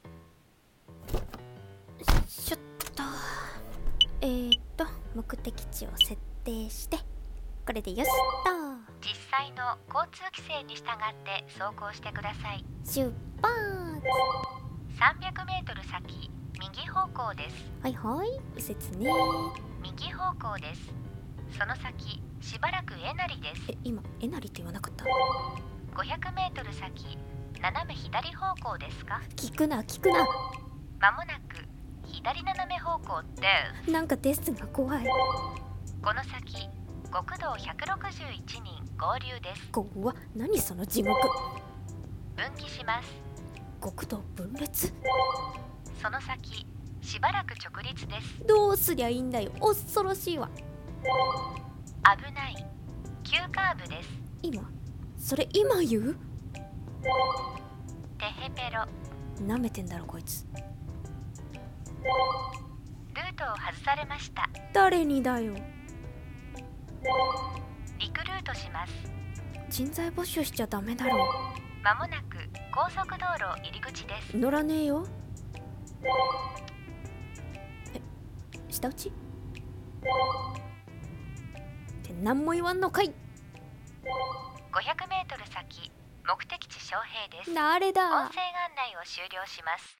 【ドライブ】2人声劇